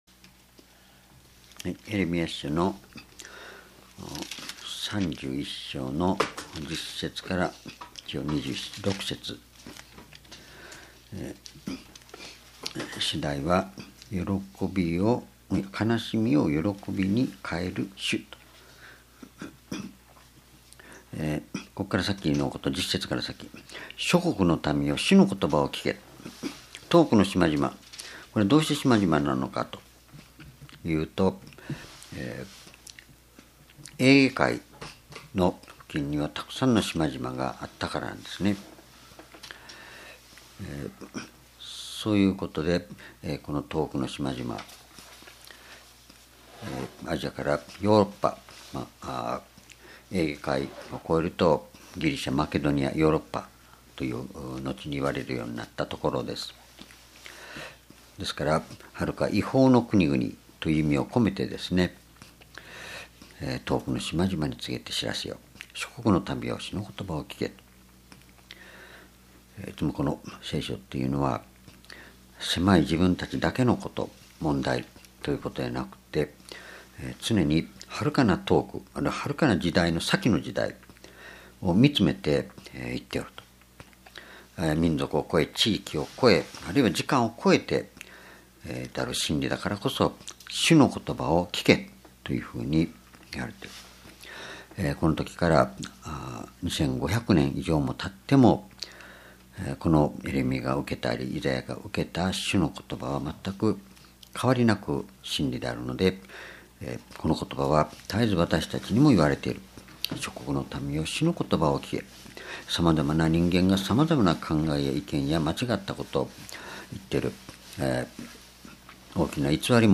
主日礼拝日時 2017年4月4日（夕拝） 聖書講話箇所 エレミヤ書31章10-17 「悲しみを喜びに変える主」 ※視聴できない場合は をクリックしてください。